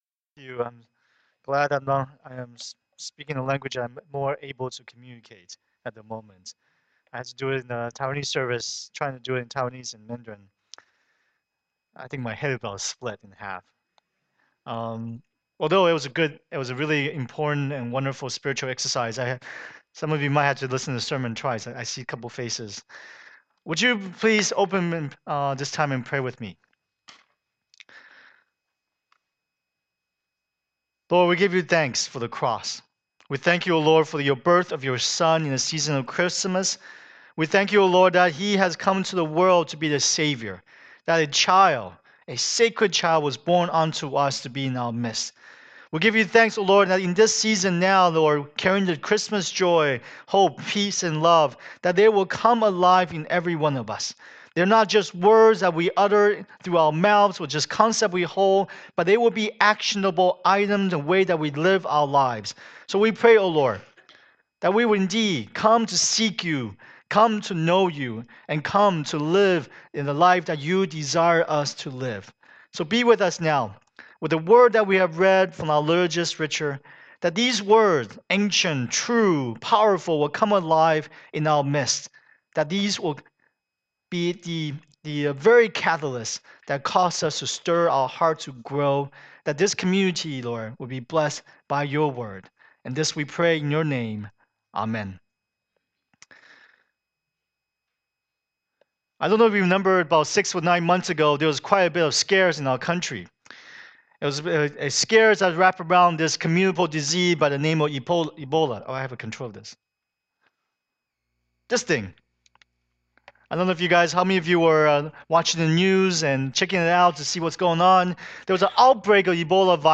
Passage: John 1:43-51 Service Type: Lord's Day